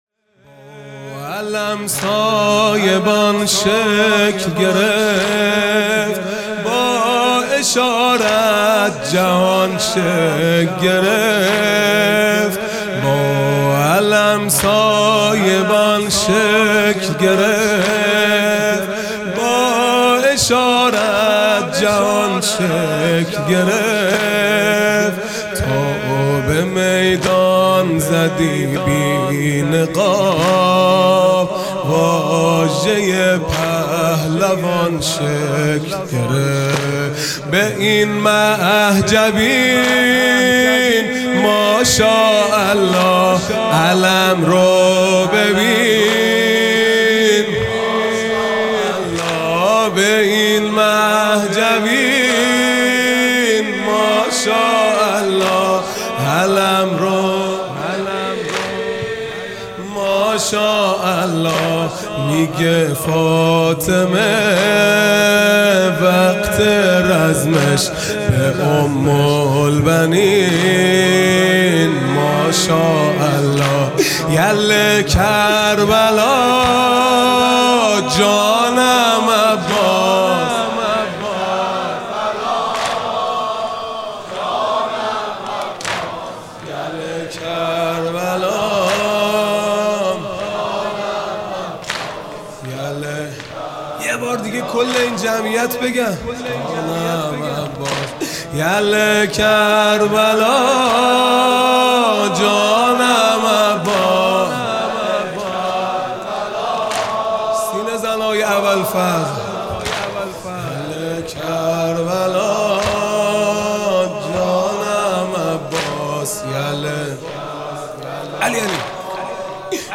دهه اول محرم الحرام ۱۴۴۵ | شب تاسوعا